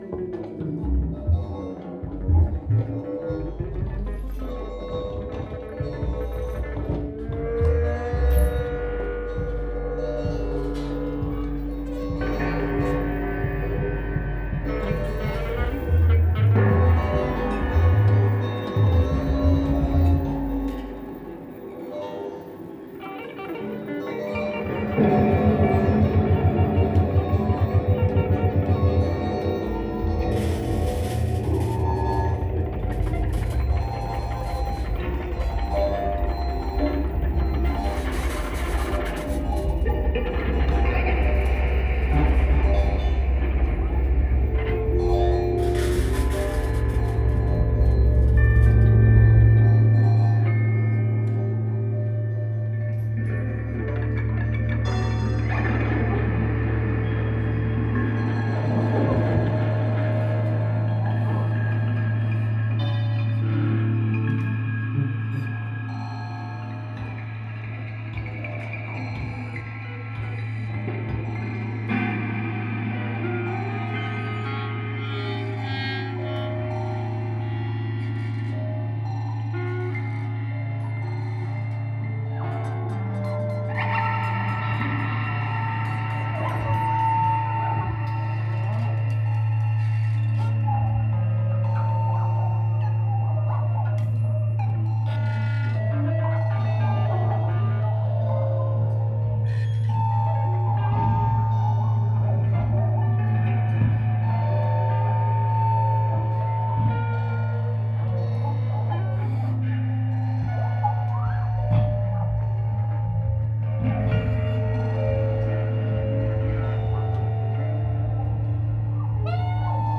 EISDUC: encuentro de improvisación, situación, diferencia y unidad compleja
oa-encuentro-eisduc-tutti.mp3